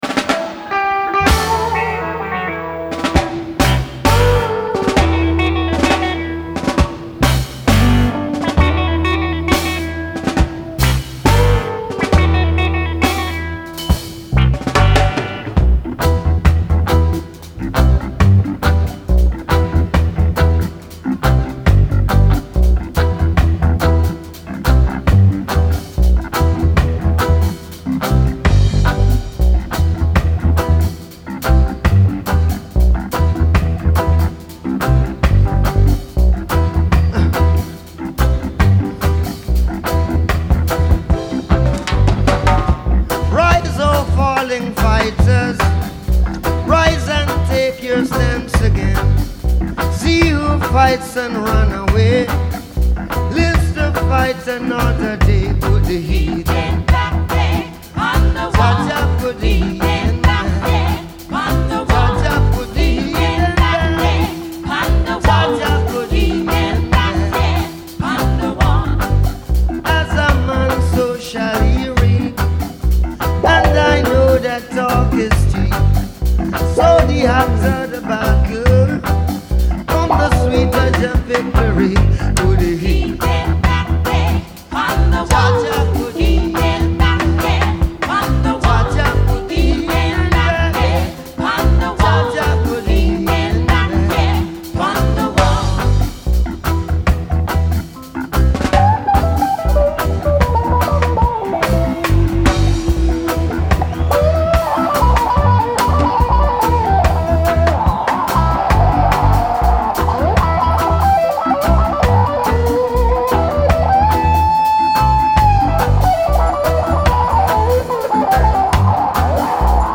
Genre : Reggae, Musiques du monde
Live At The Rainbow Theatre, London